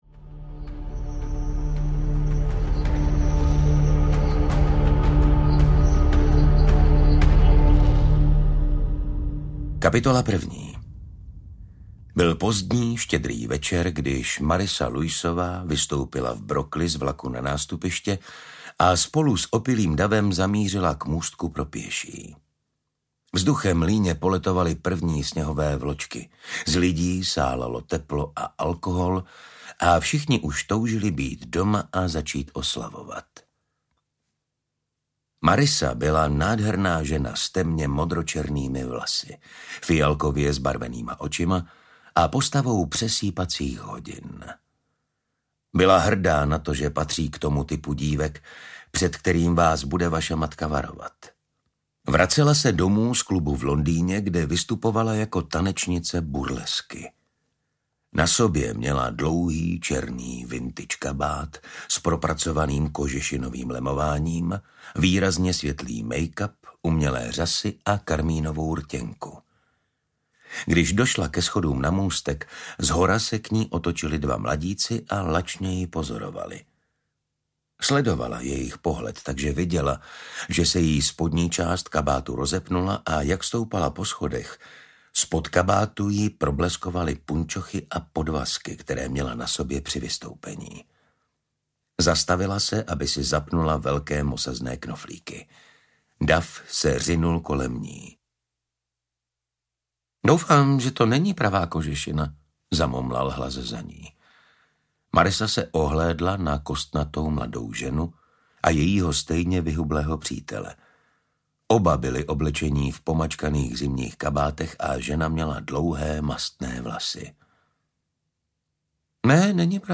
Smrtící tajnosti audiokniha
Ukázka z knihy